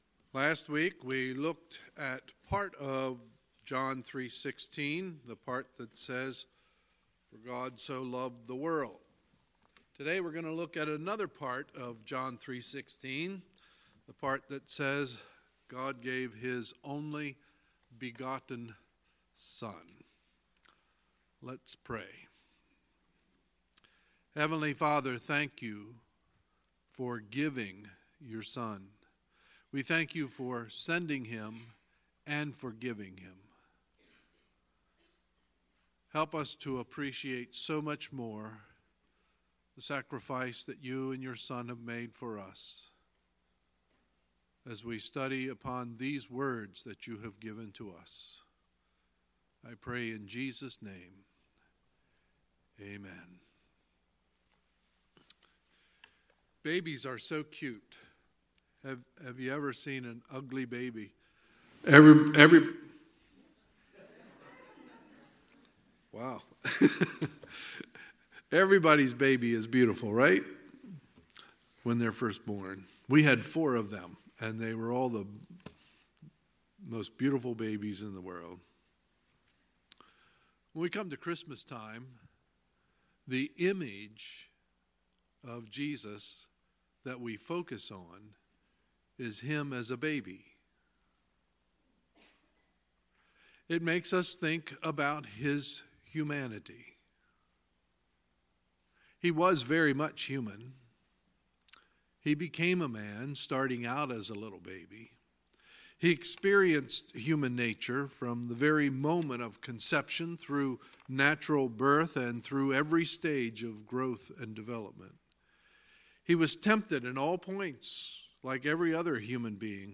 Sunday, December 9, 2018 – Sunday Morning Service